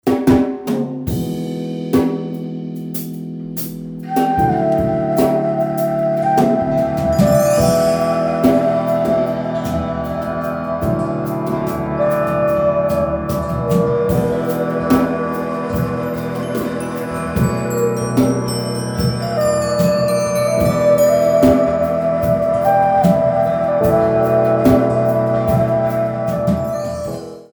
four beats